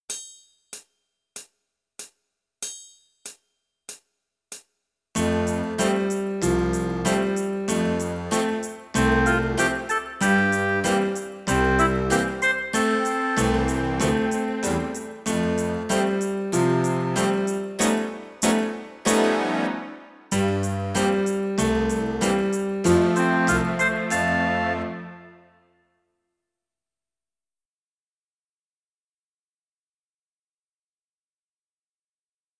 • instrumentgrupp: Piano eller klaviatur
• instrumentering: Piano